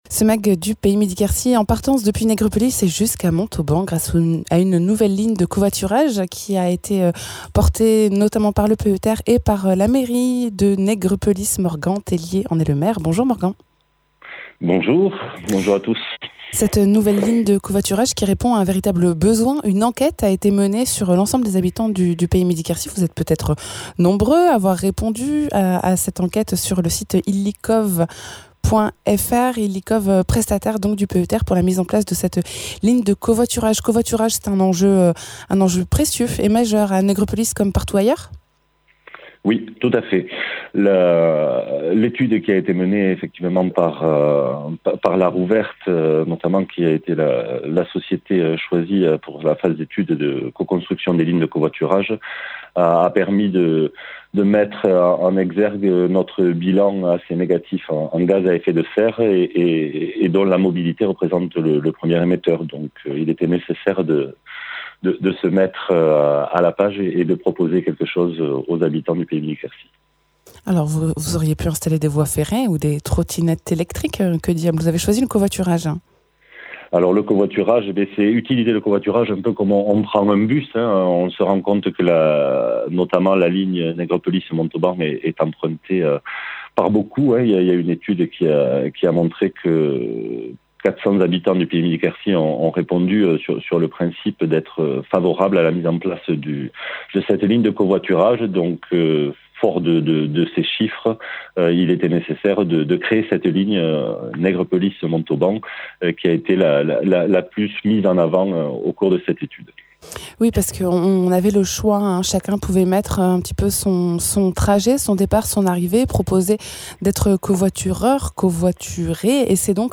La ligne de covoiturage entre Nègrepelisse et Montauban a été mise en place : témoignage du maire de Nègrepelisse.
Invité(s) : Morgan Tellier, maire de Nègrepelisse